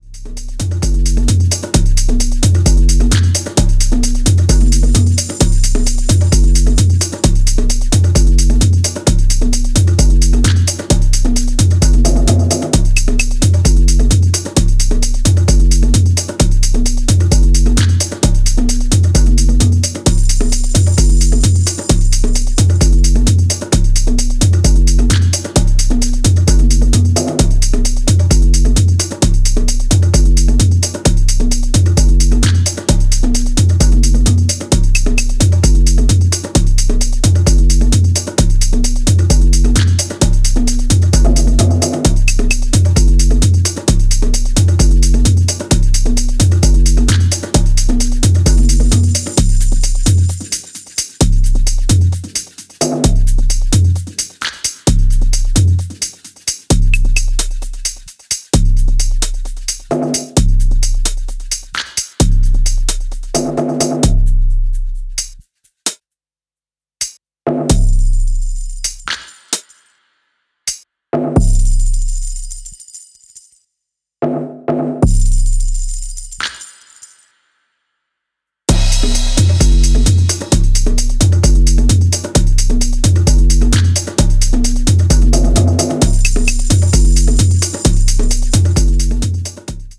ミッド/ローにかけての土臭いビートとハイハットのポリリズムが幻惑的な